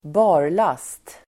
Uttal: [²b'a:r_las:t el. b'a:las:t]